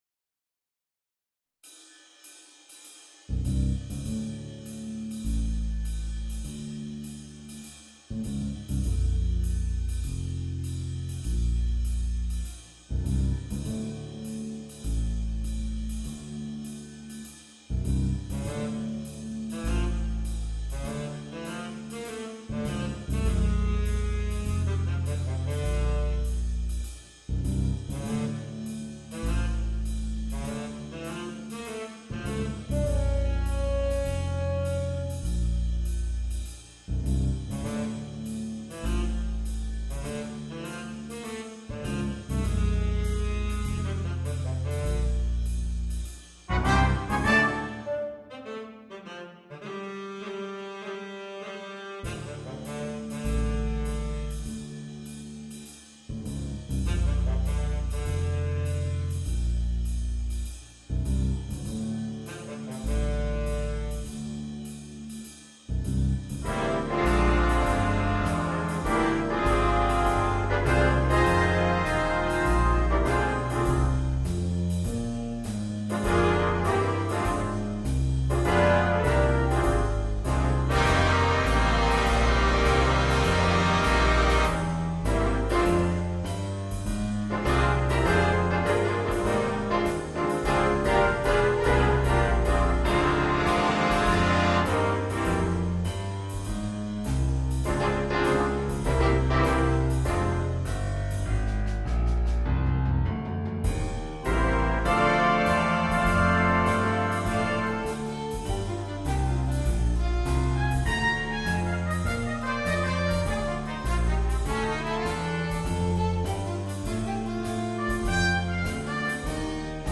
для биг-бэнда